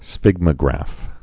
(sfĭgmə-grăf)